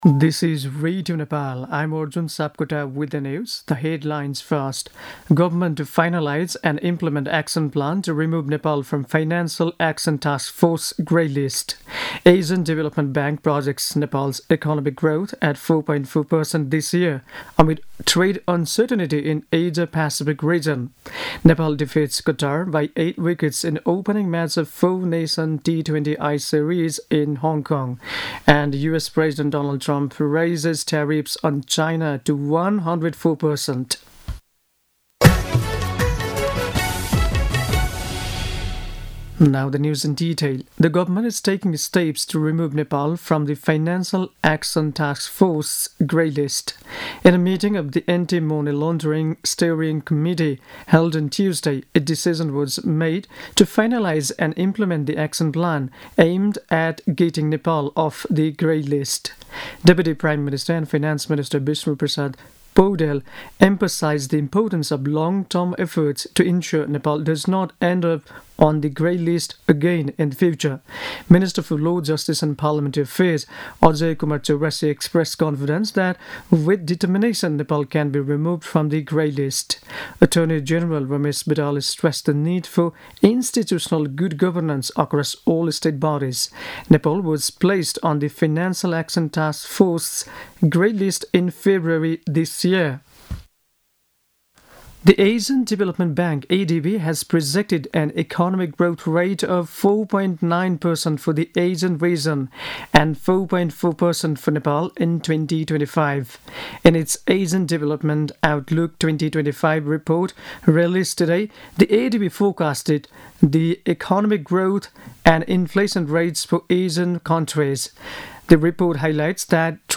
दिउँसो २ बजेको अङ्ग्रेजी समाचार : २७ चैत , २०८१
2-pm-news.mp3